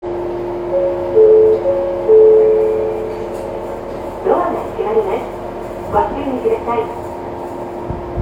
“ŒŠC“¹VŠ²ü‚ÌƒhƒAƒ`ƒƒƒCƒ€BˆÓŠO‚ÆA’†¬Ž„“S‚ÅÌ—p—á‚ª‚ ‚Á‚½‚è‚µ‚Ü‚·B®A–Ü˜_ŽÔŠOƒXƒs[ƒJ[‚Í–³‚¢‚Ì‚ÅŽÔ“à‚Å‚Ì–Â“®‚Å‚·B